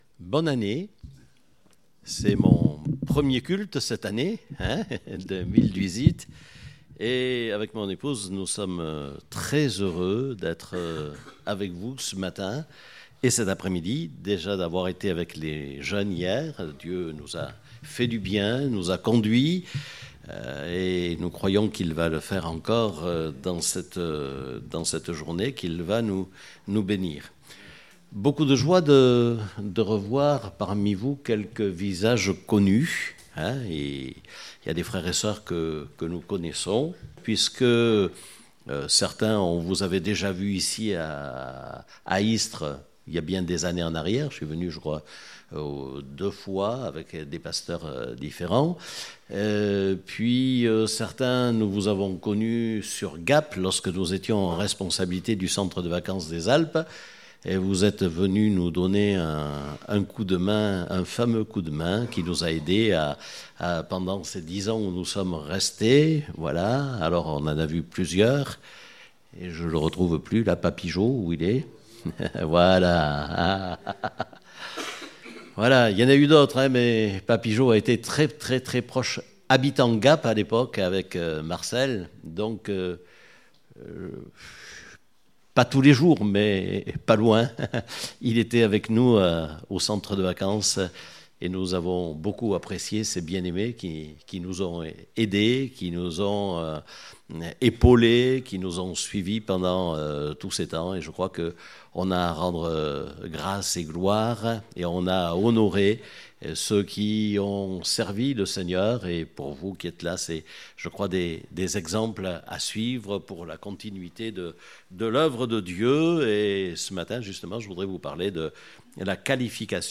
Date : 7 janvier 2018 (Culte Dominical)